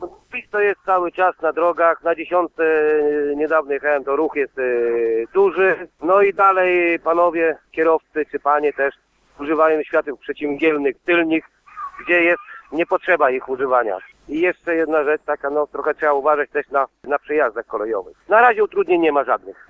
– mówi jeden z naszych słuchaczy.